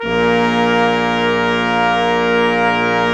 Index of /90_sSampleCDs/Roland LCDP06 Brass Sections/BRS_Quintet/BRS_Quintet long